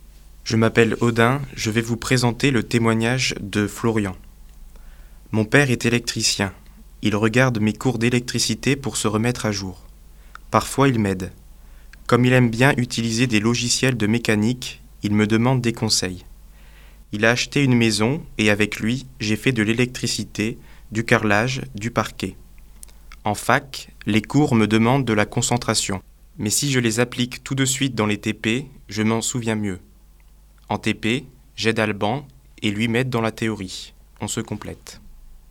étudiants français et chinois de l'Université Lille 1 qui ont prêté leur voix.